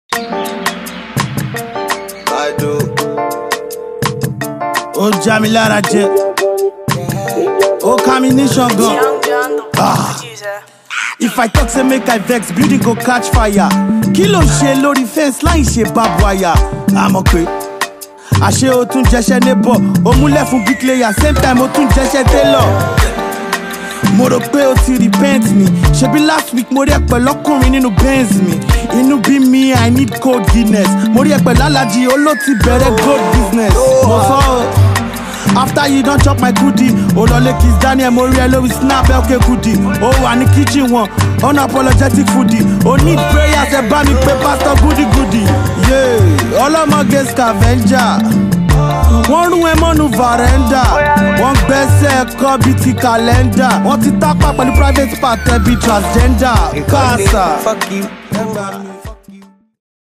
short but heavyweight rap